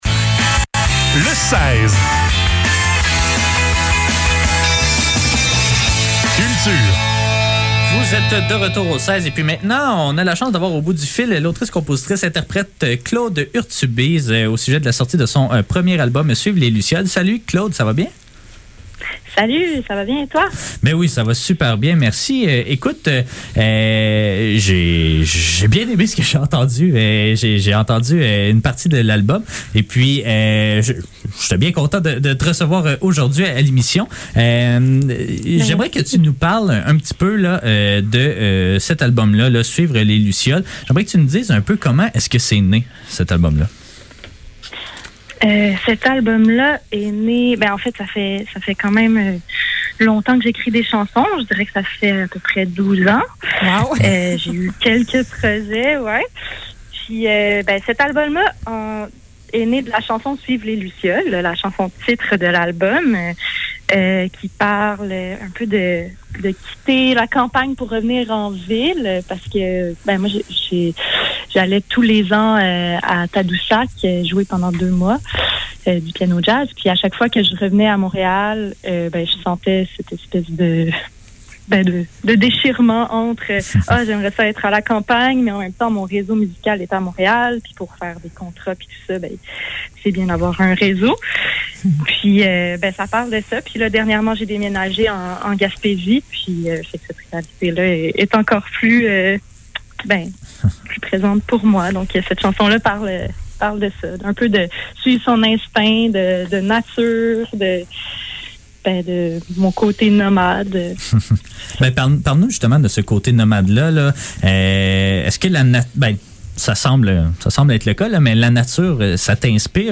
Les entrevues de CFAK Le seize - Entrevue